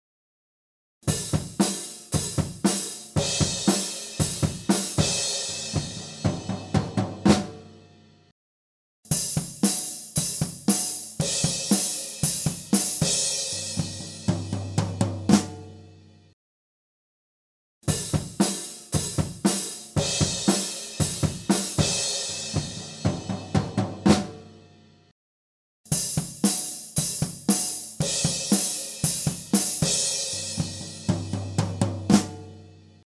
Διερεύνηση στερεοφωνικών τεχνικών ηχογράφησης και μίξης κρουστού οργάνου (drums) μέσω διαφορετικών στερεοφωνικών τεχνικών μικροφώνων.